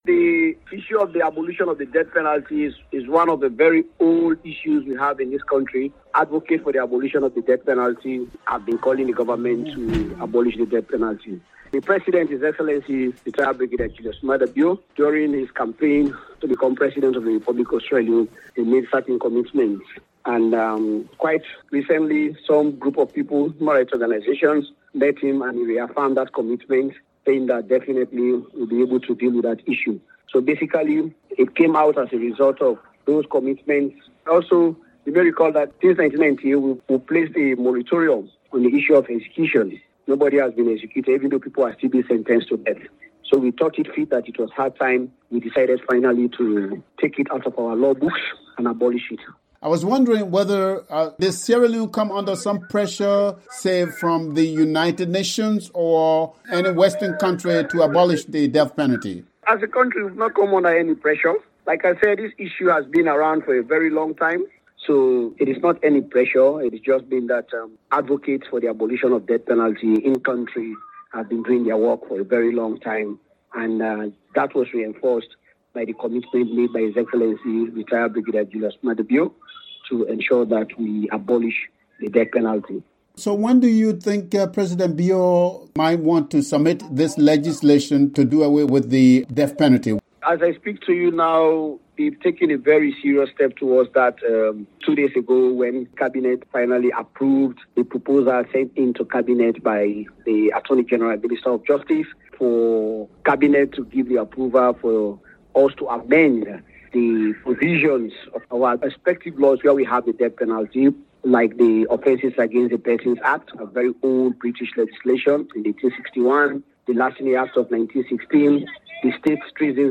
spoke to deputy justice minister Umaru Napoleon